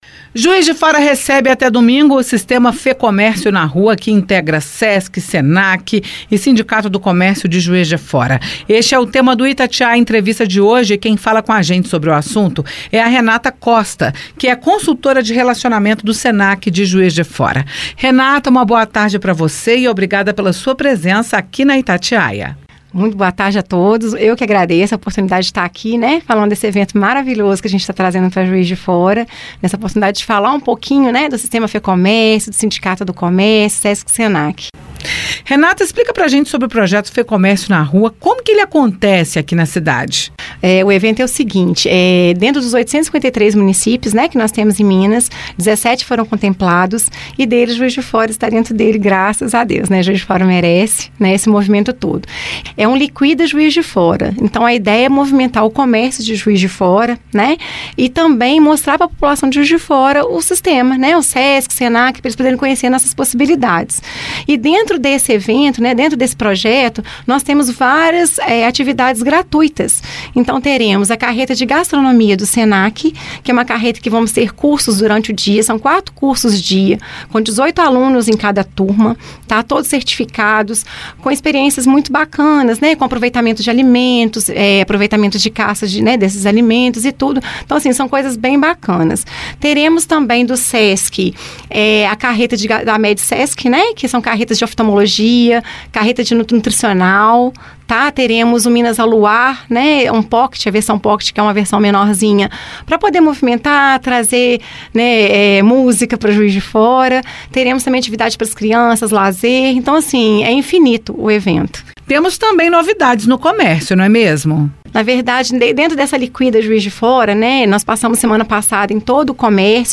jf-juiz-de-fora-Itatiaia-Entrevista-Fecomercio-na-Rua.mp3